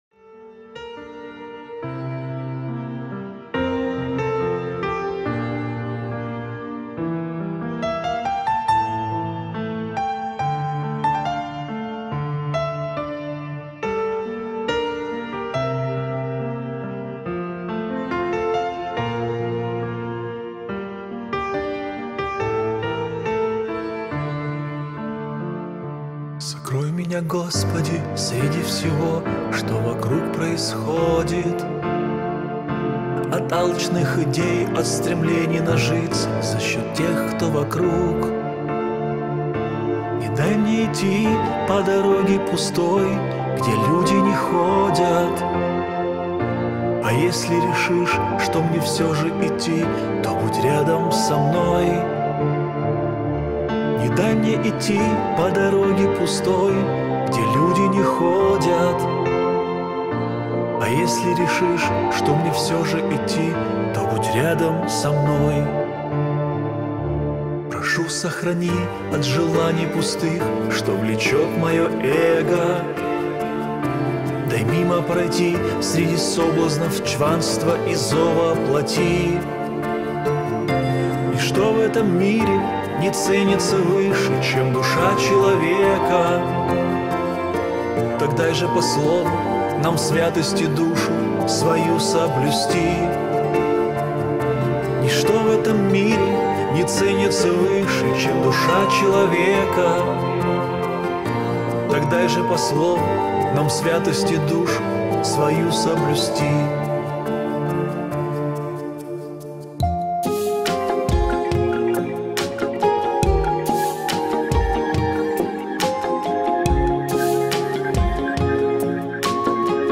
124 просмотра 73 прослушивания 5 скачиваний BPM: 75